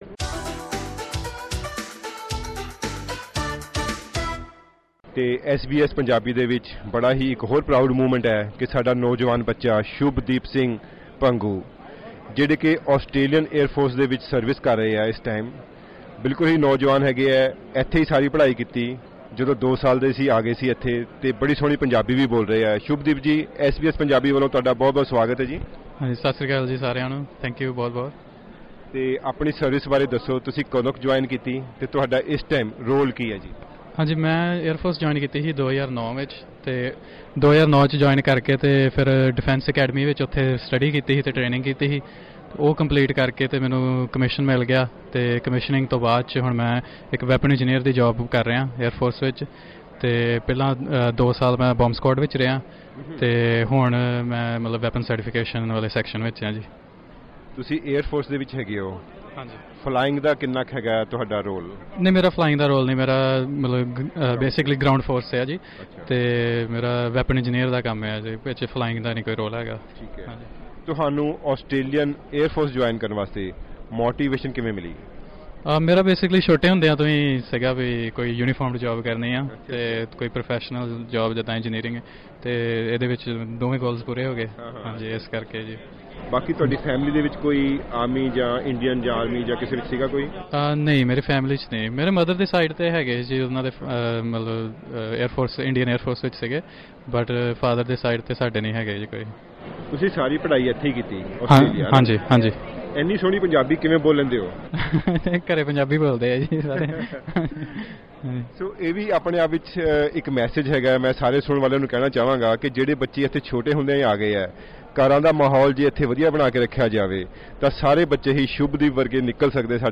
In our series of interviews with many participants of the Sikh contingent in Sydney's Anzac Day parade, here are some very special interviews.